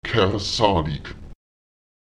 Lautsprecher kerzálek [kErÈsaùlek] sich verrechnen